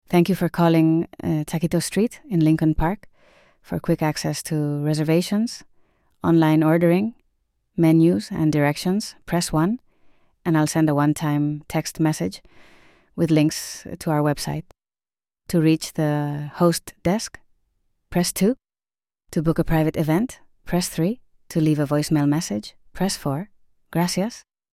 Customize your voice greetings and caller journey with authentic AI voices that match your brand.
Spanish Female (US)